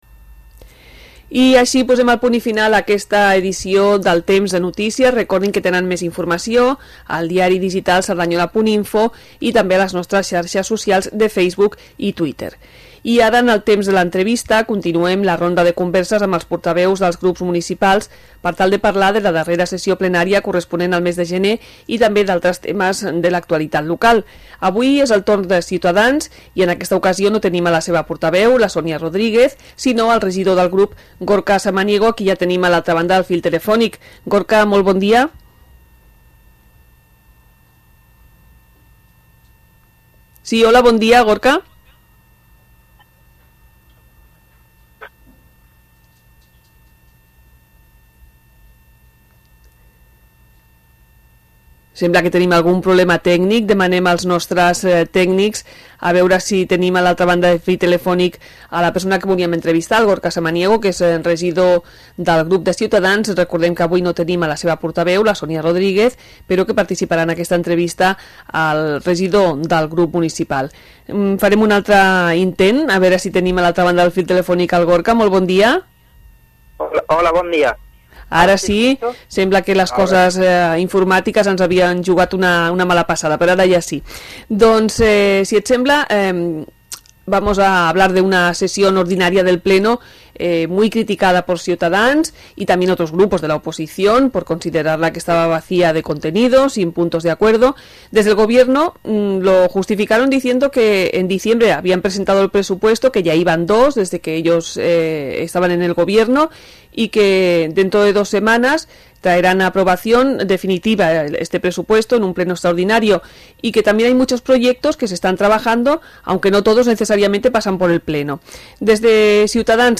Entrevista-Gorka-Samaniego-regidor-Cs.mp3